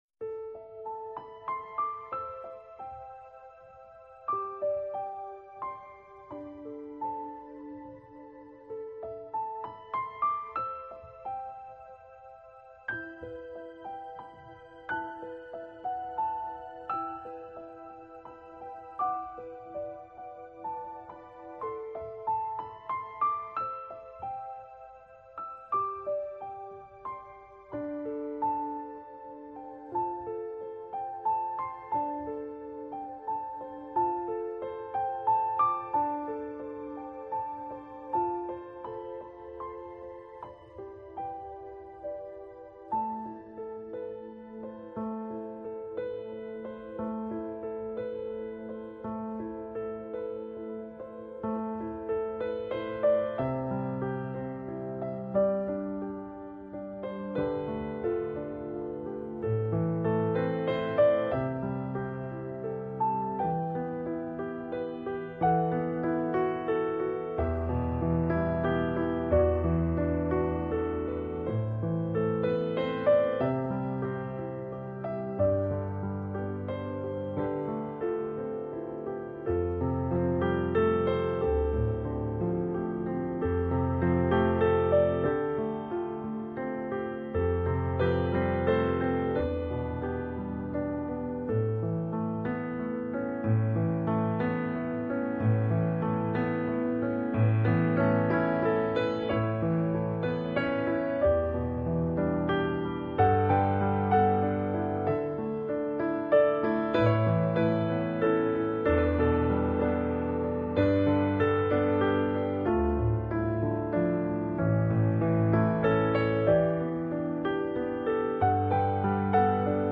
【优美钢琴】
音乐风格: New AGE
新、流畅、舒缓，无一不透露着自然的唯美、和谐。